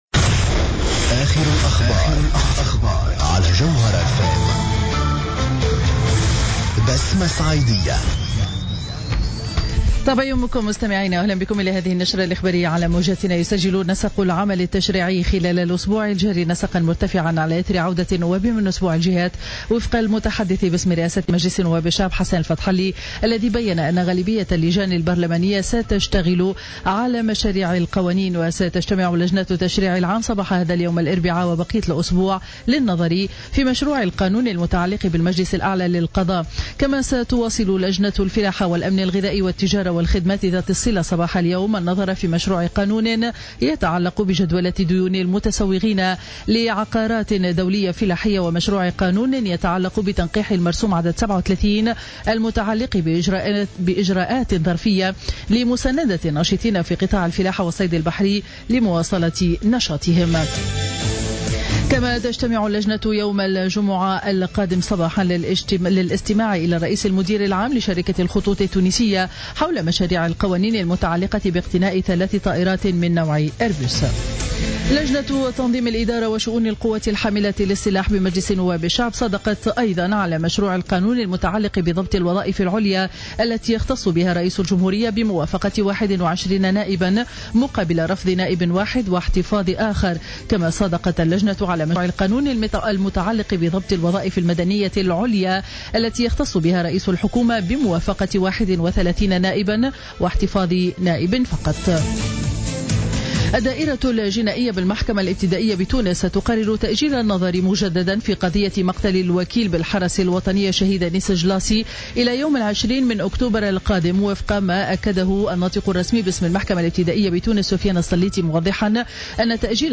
نشرة أخبار السابعة صباحا ليوم الأربعاء 24 جوان 2015